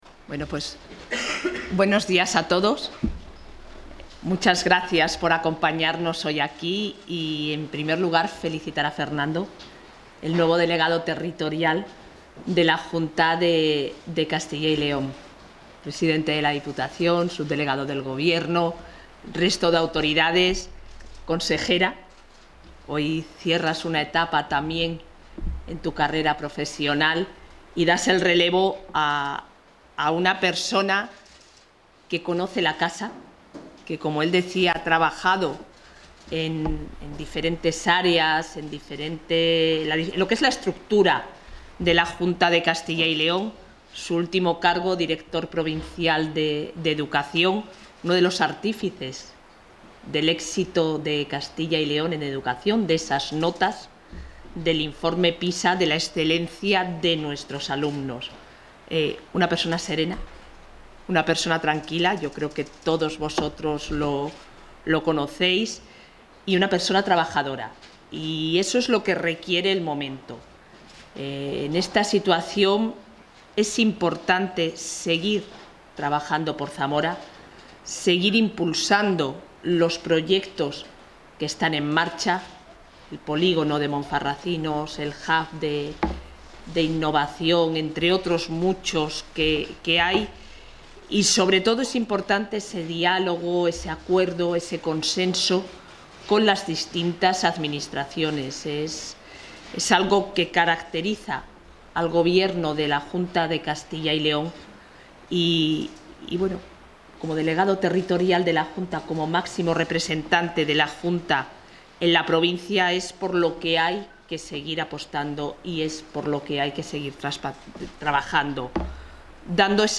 Toma de posesión del delegado territorial de la Junta en Zamora
Intervención de la vicepresidenta de la Junta.
La vicepresidenta de la Junta de Castilla y León, Isabel Blanco, ha presidido esta mañana el acto de toma de posesión del nuevo delegado territorial de Zamora, Fernando Prada, al que también ha asistido la consejera de Industria, Comercio y Empleo, Leticia García.